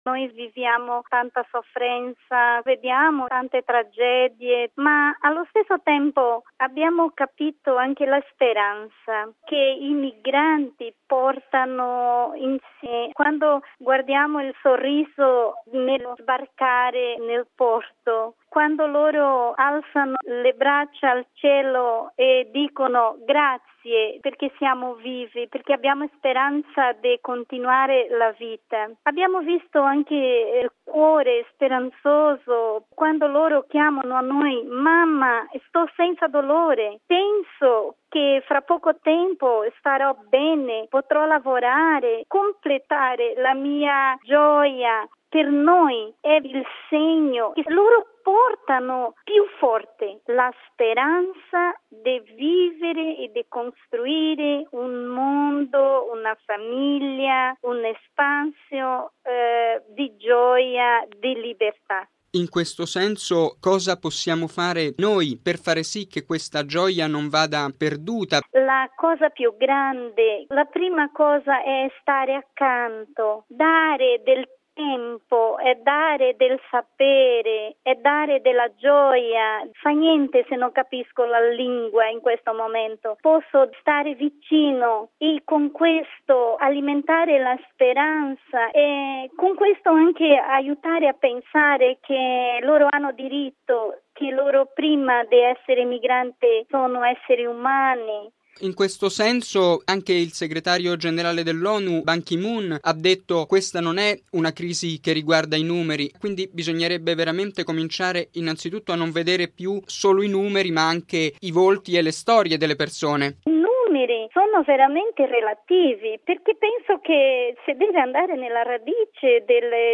Questa è la sua testimonianza: